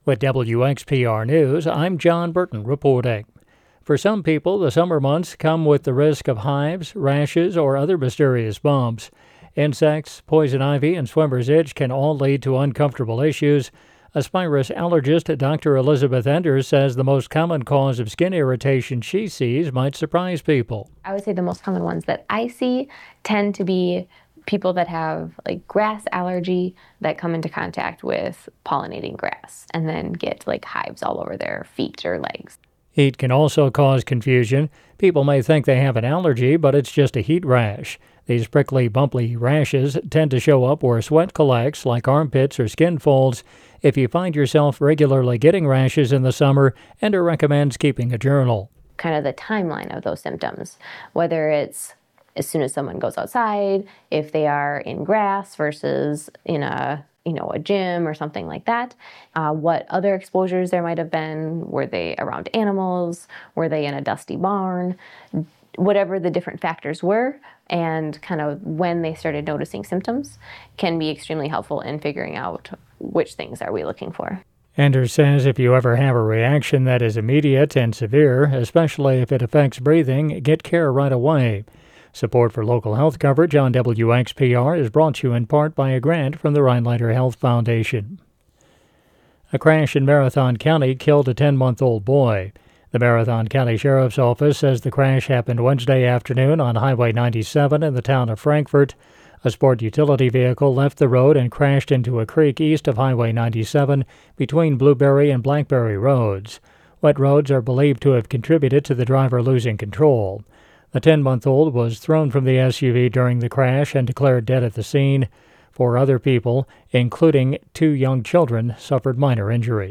The Extra is WXPR’s daily news podcast. Get the news you need to stay informed about your local community – all in six minutes or less.